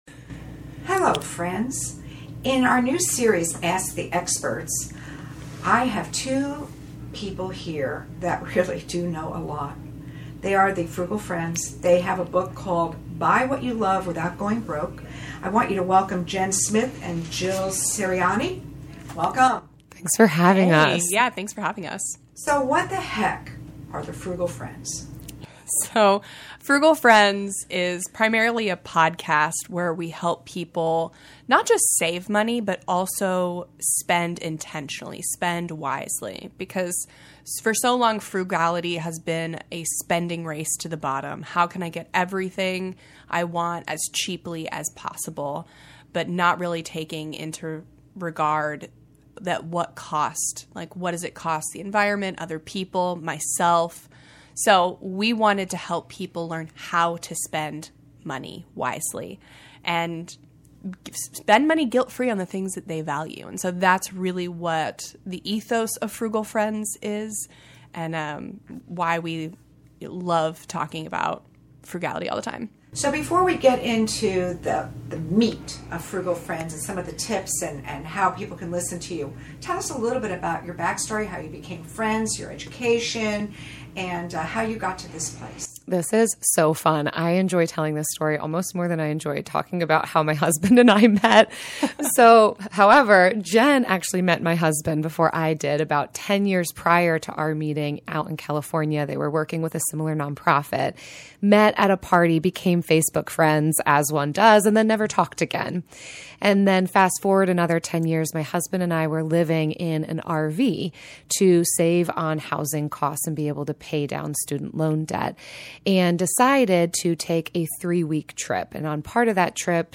ASK AN EXPERT